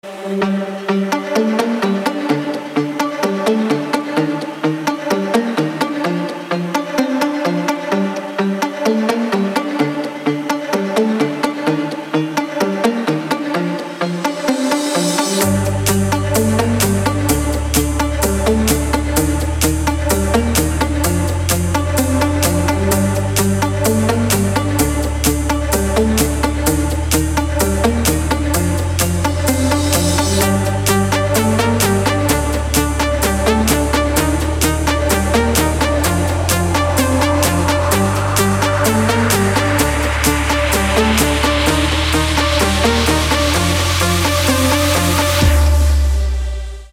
• Качество: 320, Stereo
dance
спокойные
без слов
club
Big Room
progressive house
house
Основной стиль: Big Room House.